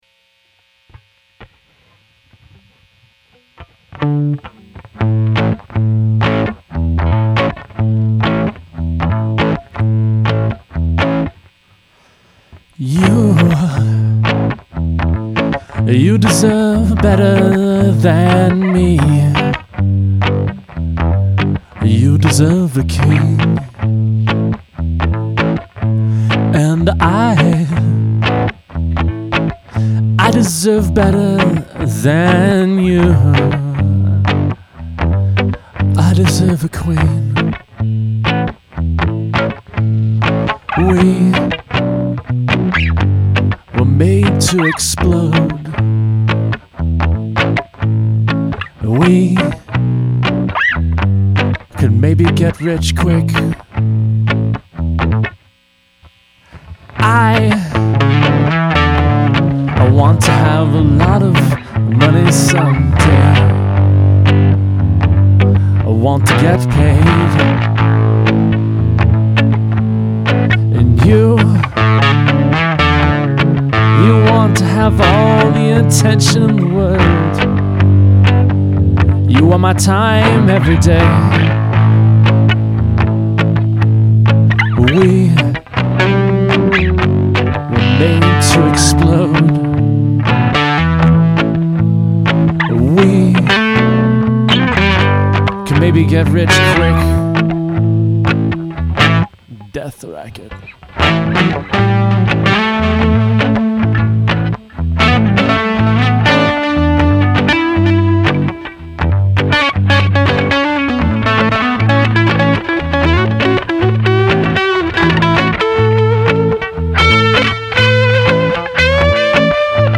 Nice guitar, adds a nice melody of its own.
I love the sounds and tone and voice in this one, really present and approachable.